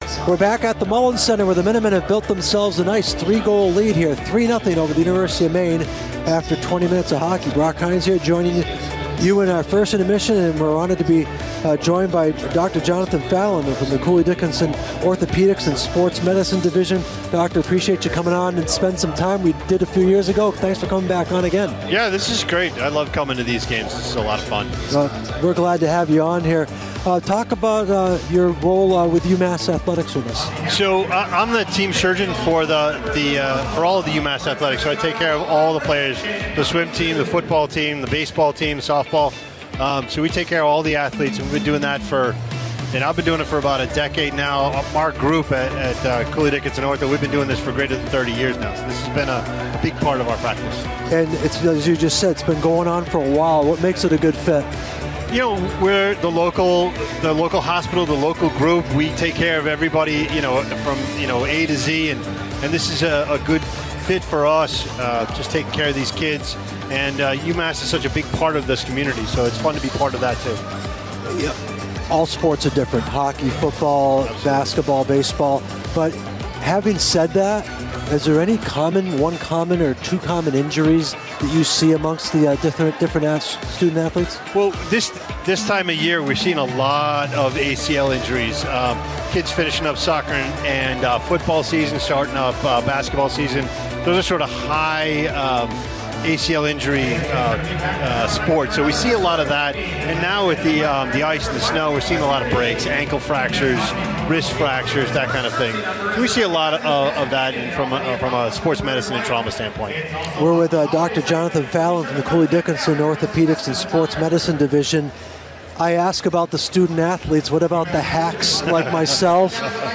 during a UMass Hockey radio broadcast